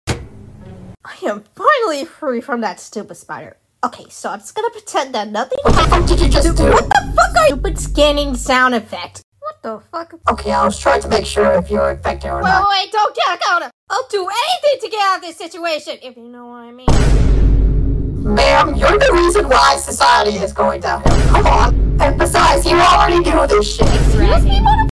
just short voiceover I did, lol😀